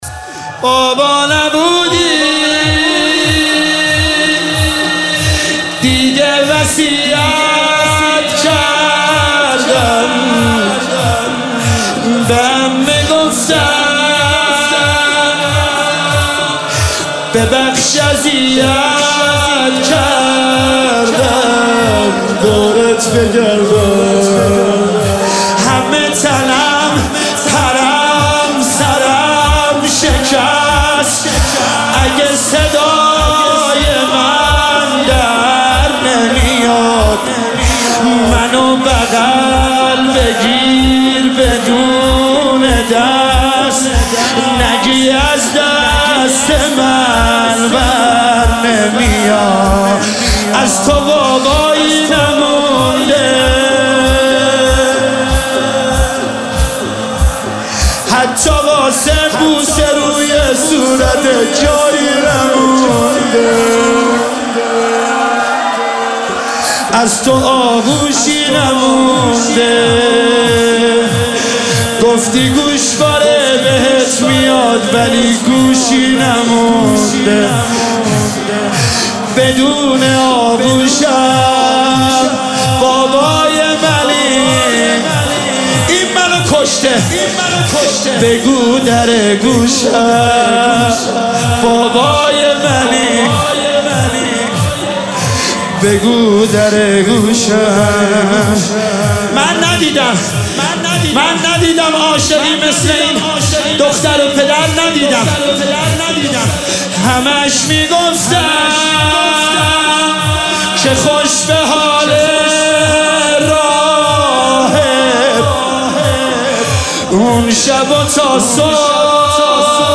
دانلود فایل صوتی مداحی زمینه حضرت رقیه